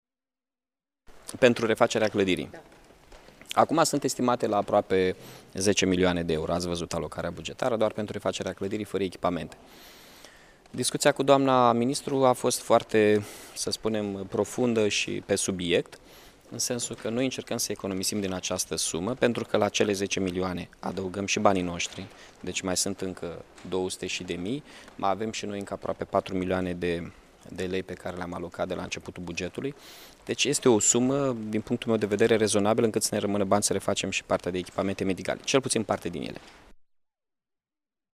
Mihai Chirica a precizat ca banii din asigurarea clădirii au ajuns în conturile municipalității. Un calcul făcut de primarul municipiului Iași